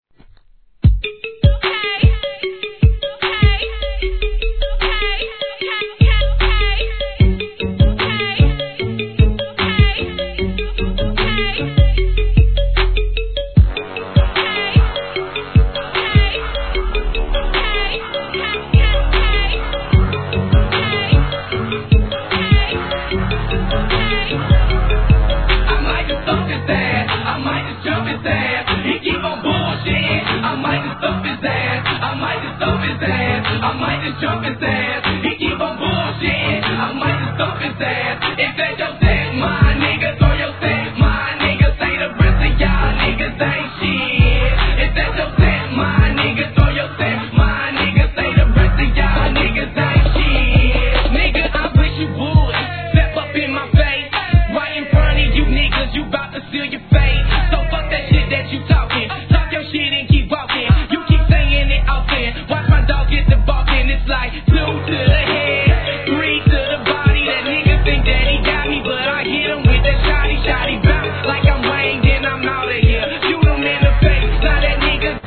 HIP HOP/R&B
両面共にDEEPなサウスサウンドに好き者なら必ず中毒に★